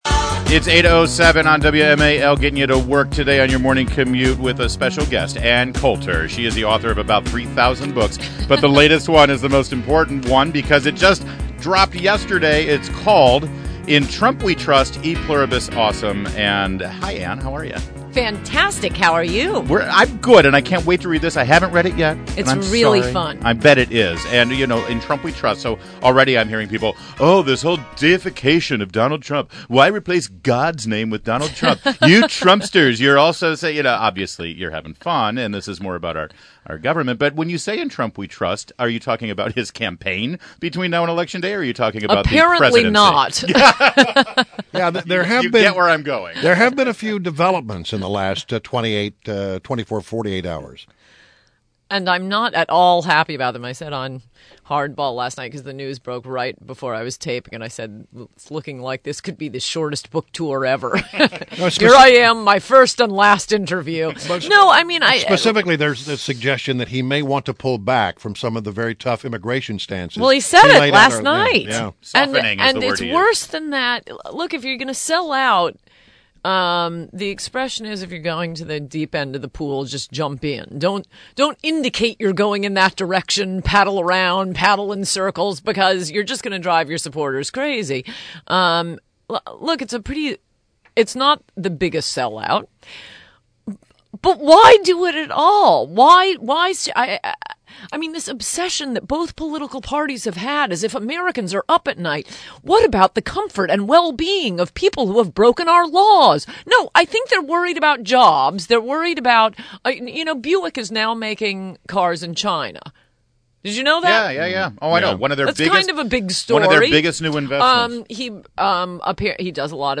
WMAL Interview - ANN COULTER - 08.24.16